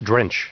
Prononciation du mot drench en anglais (fichier audio)
Prononciation du mot : drench